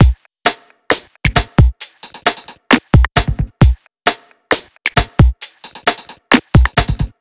HP133BEAT2-L.wav